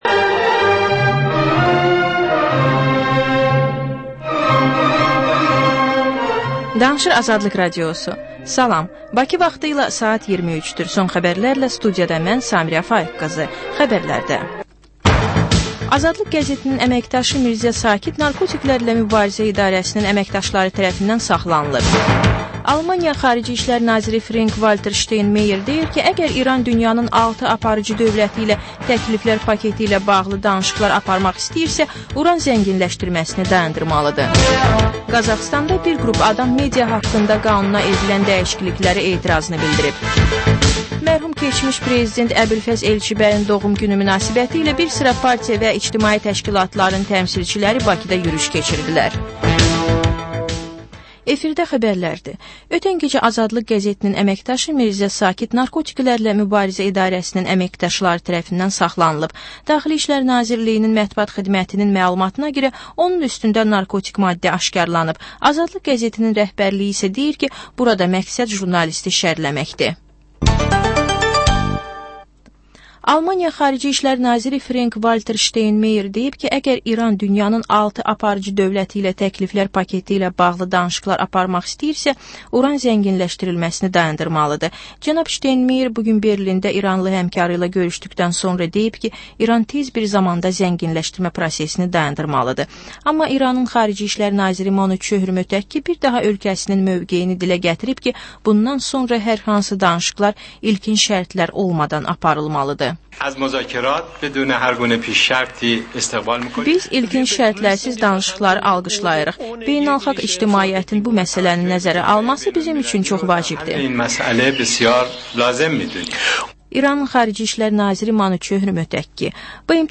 Xəbərlər, reportajlar, müsahibələr. Panorama: Jurnalistlərlə həftənin xəbər adamı hadisələri müzakirə edir.